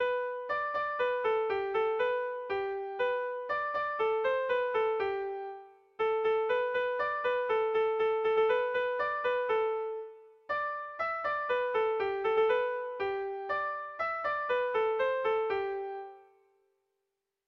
Erromantzea
Azkaine < Lapurdi Itsasegia < Lapurdi < Euskal Herria
8A / 8A / 10 / 8A / 8A / 8A (hg) | 8A / 8A / 18A / 8A / 8A (ip)
ABDDEE